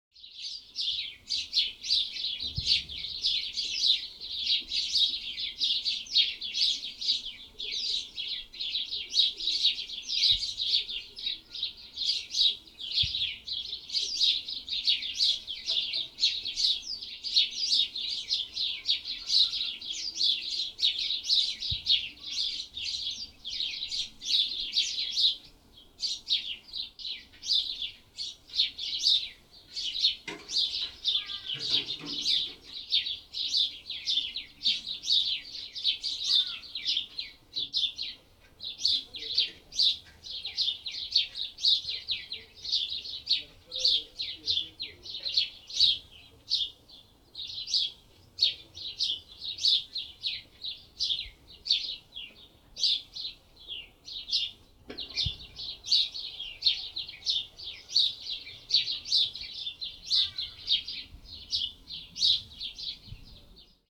birds_gorod.mp3